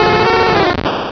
Cri de Goupix dans Pokémon Rubis et Saphir.